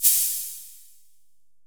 808MP70MAR.wav